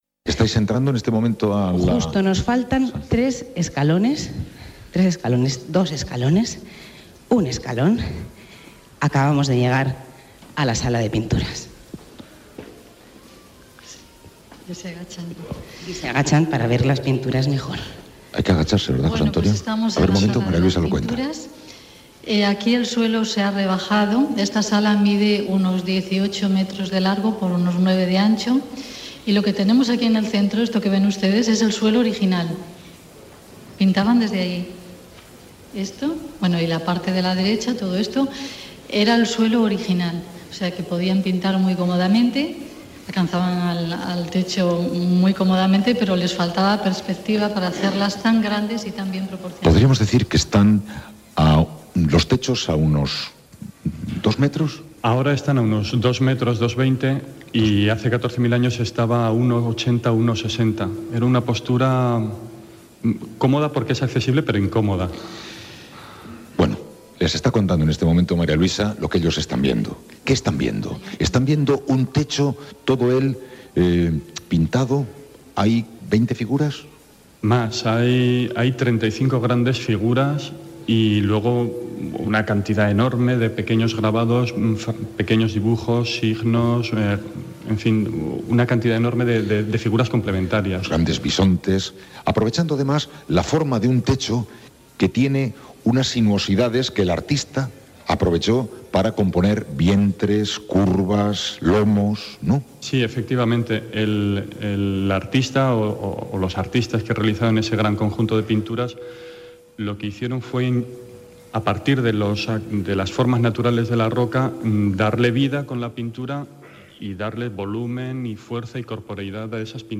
Narració des de la sala de pintures de la cova d'Altamira a Santillana del Mar (Cantàbria)
Info-entreteniment